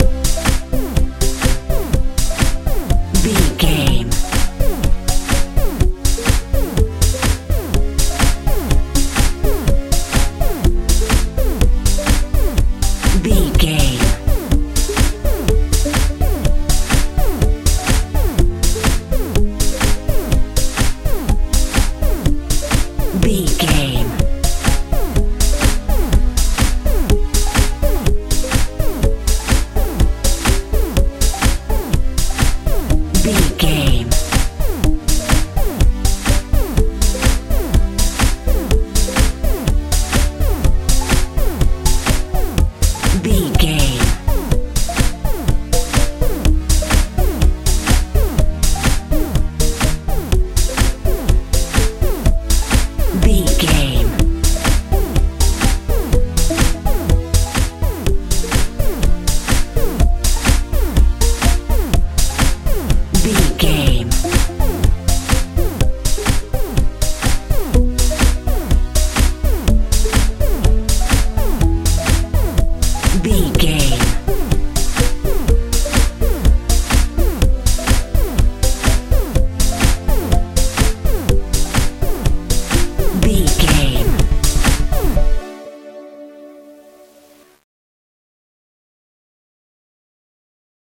modern dance
Ionian/Major
playful
bass guitar
synthesiser
drums
strange
uplifting
mechanical
futuristic
bouncy